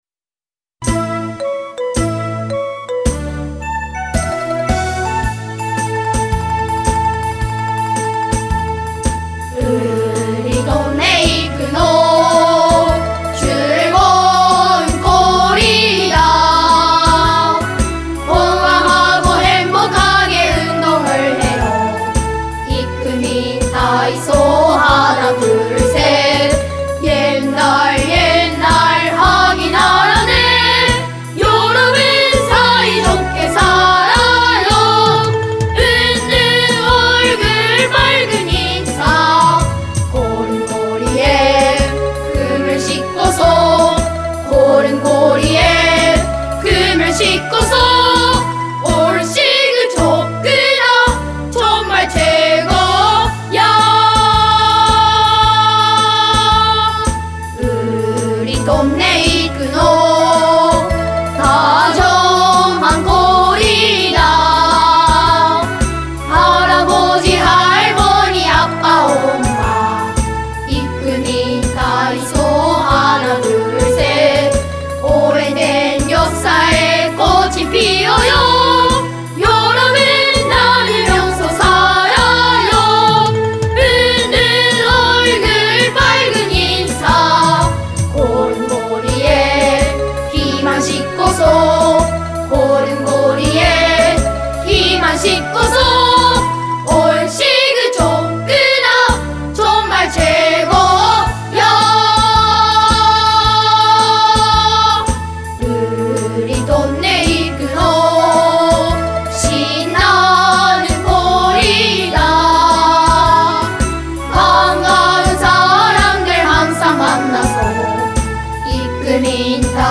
作曲・吉田あゆみ　／編曲・浅野　博司　／唄・生野初級学校男声重唱部